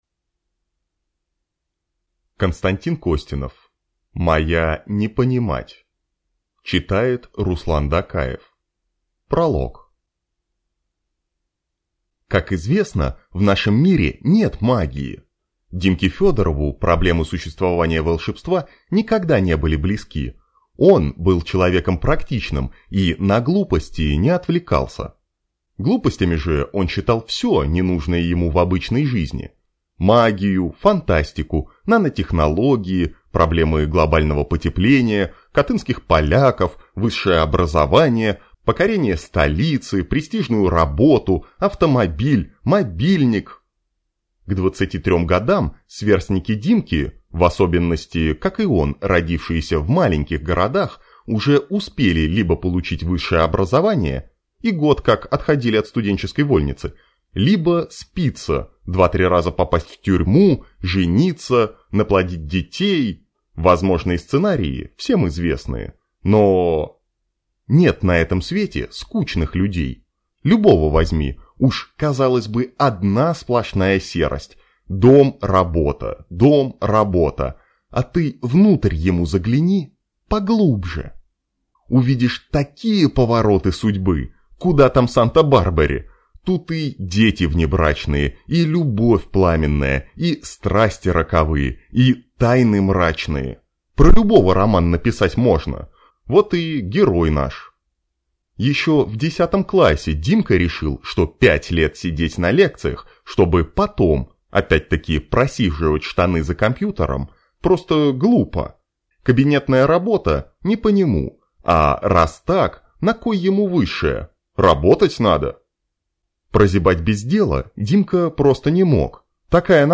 Аудиокнига Моя не понимать | Библиотека аудиокниг
Прослушать и бесплатно скачать фрагмент аудиокниги